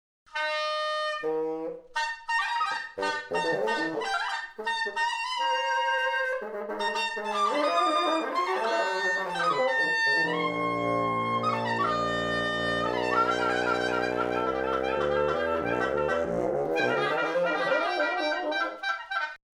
duo de bois de vents de bruxelles
musique instrumental guillerettes dissonantes ou obscure.
des morceaux assez courts